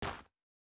Shoe Step Gravel Hard A.wav